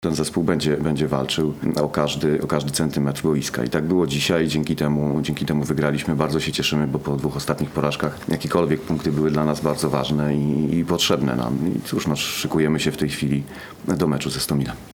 Na pomeczowej konferencji